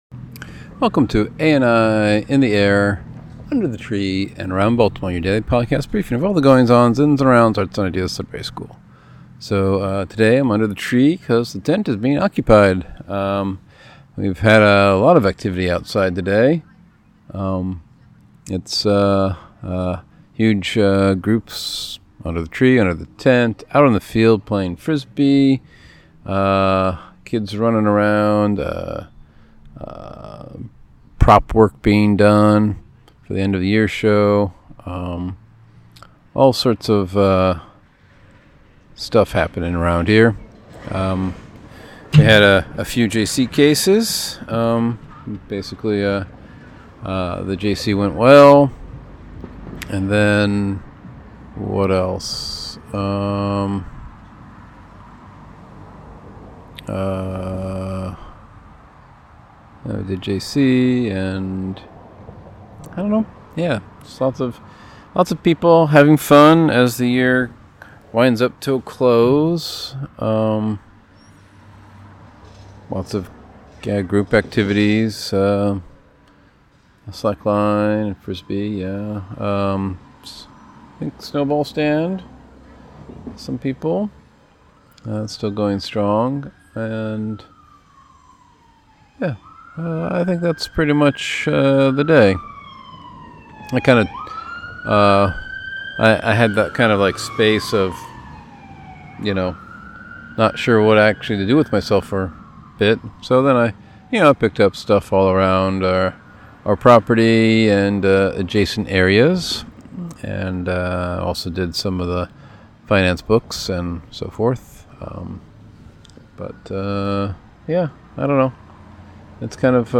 Recording under tree (tent occupied).